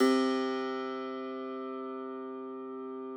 53l-pno06-C1.aif